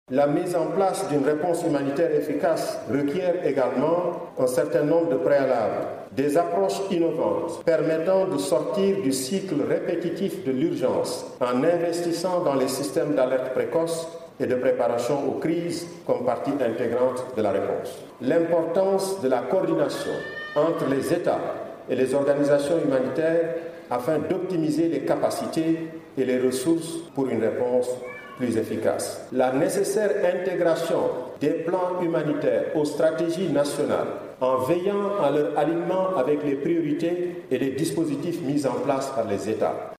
REACTION-COORDONNATEUR-HUMANITAIRE-ONU-FR.mp3